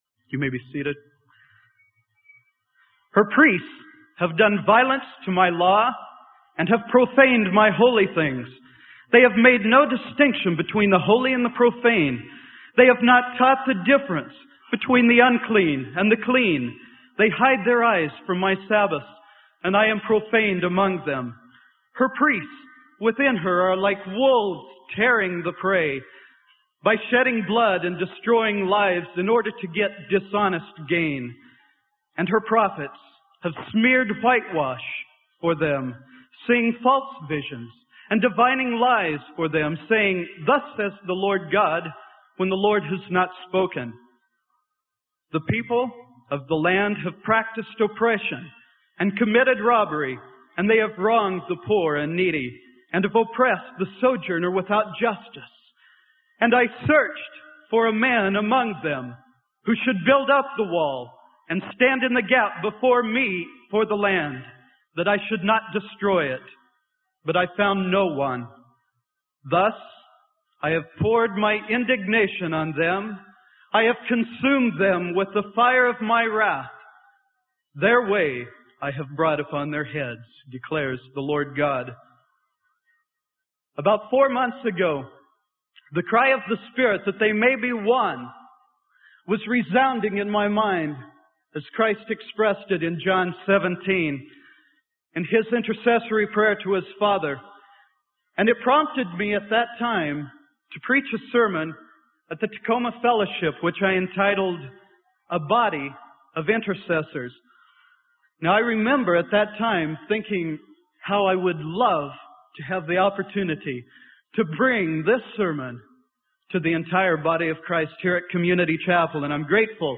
Sermon: The Body of Intercessors - Freely Given Online Library